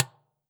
Dustbin2.wav